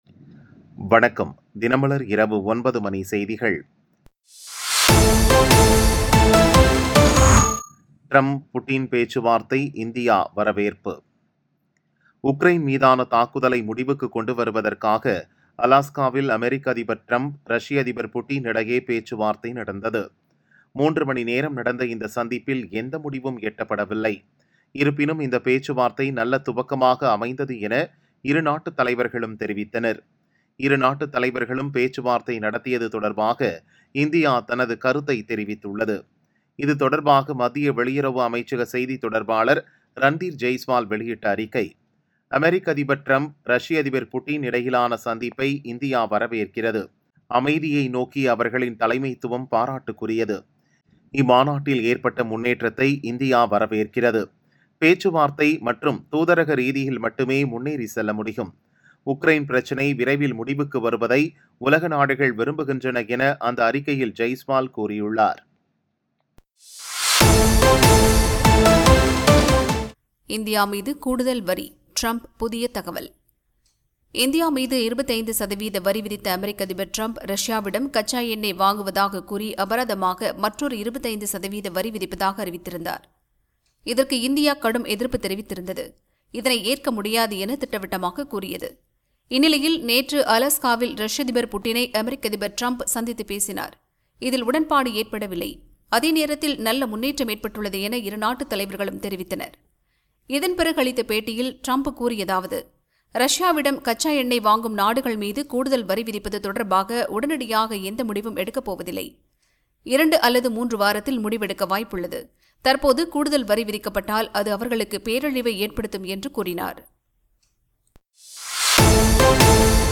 தினமலர் இரவு 9 மணி செய்திகள் 16 AUG 2025